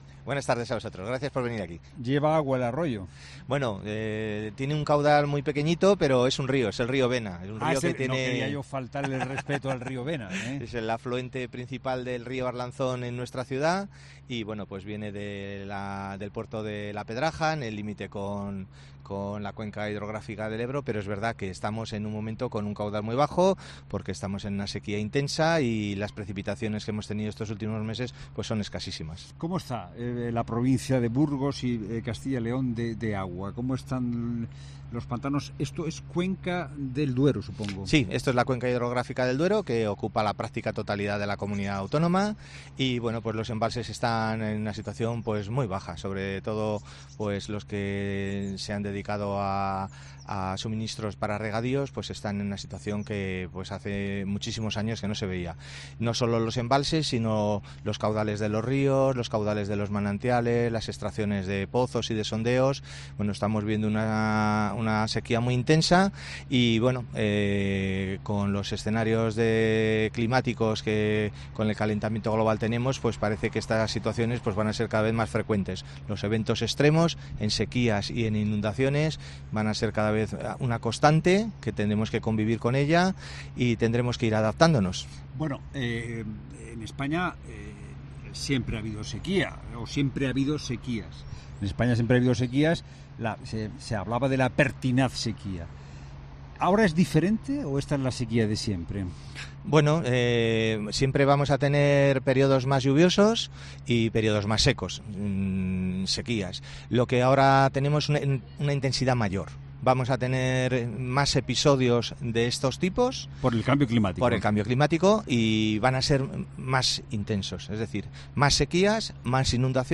Un experto explica a COPE cómo reutilizar el agua: "Hay que aprovechar las aguas superficiales y subterráneas"